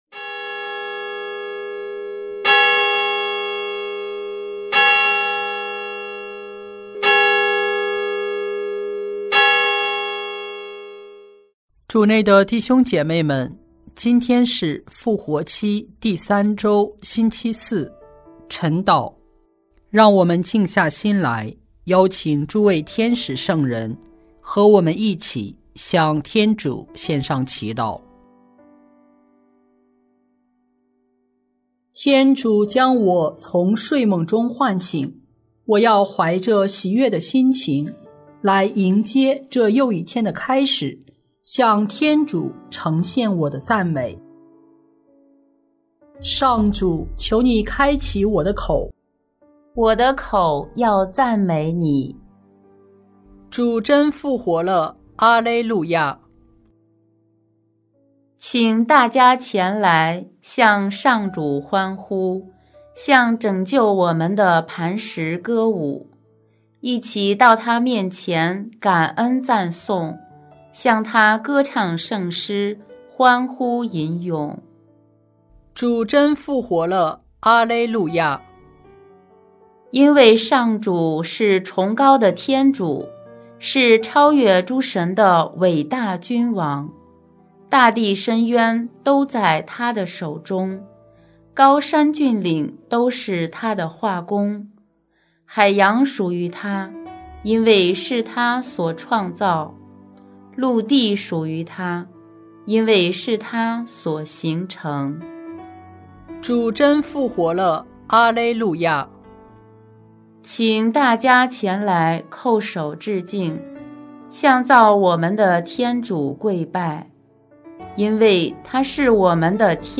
4月23日复活期第三周星期四晨祷